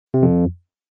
Incorrect Sound Effects_2.mp3